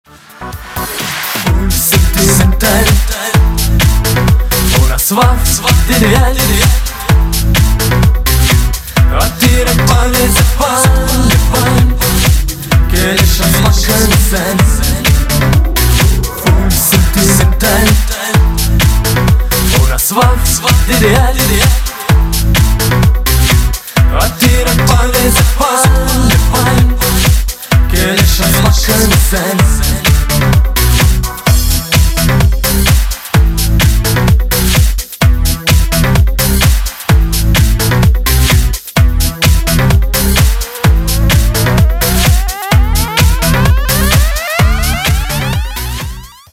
танцевальные рингтоны